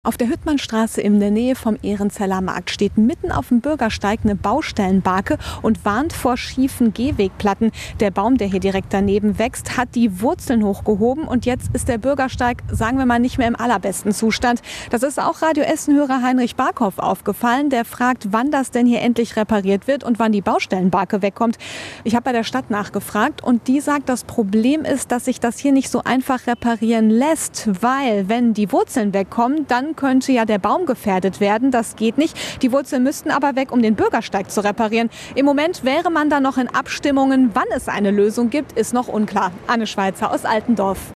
Stadtreporter